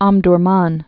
(ŏmdr-män)